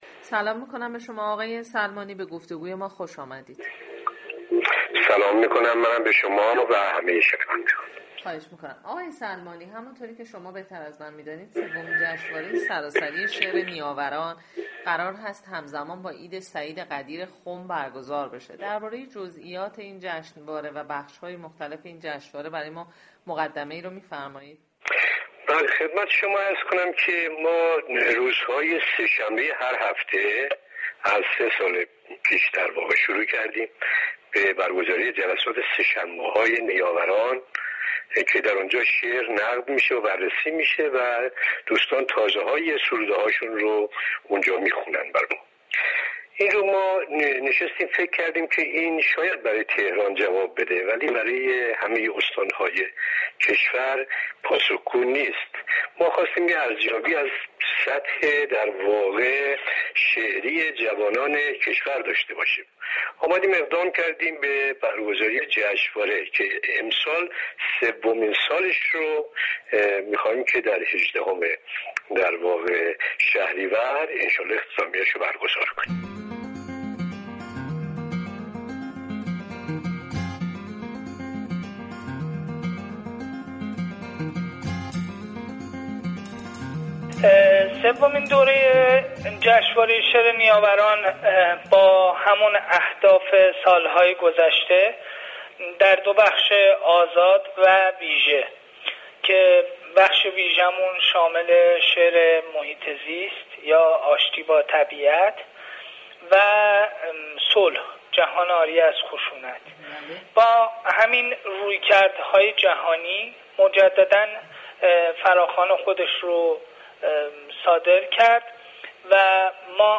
در گفتگوی اختصاصی